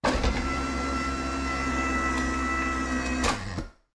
closetop.ogg